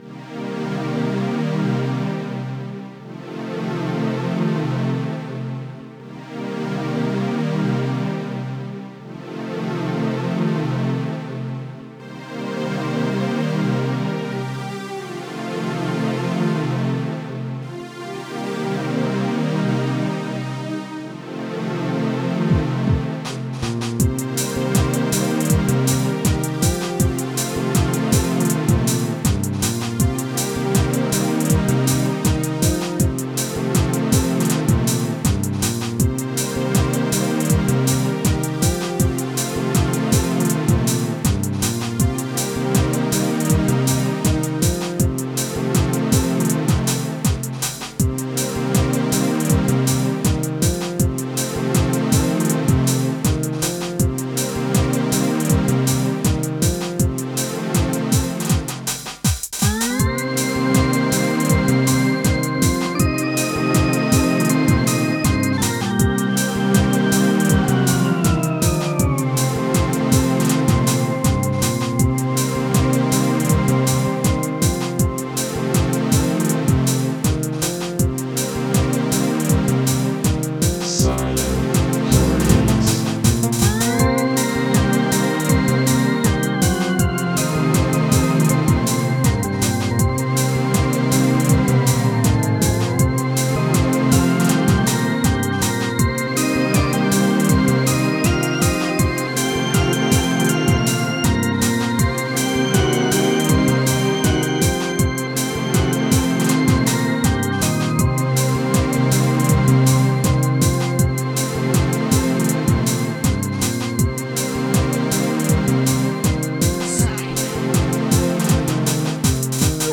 4:41 Style: Electronic Chill Out Released
A nice track for relaxing.